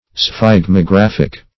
Search Result for " sphygmographic" : The Collaborative International Dictionary of English v.0.48: Sphygmographic \Sphyg`mo*graph"ic\, a. (Phusiol.) Relating to, or produced by, a sphygmograph; as, a sphygmographic tracing.